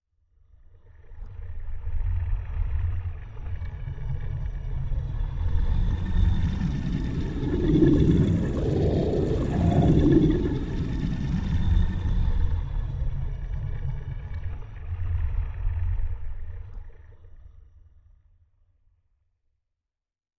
Звуки подводного мира
На глубине крошечная подлодка медленно плывет, оставляя за собой пузырьки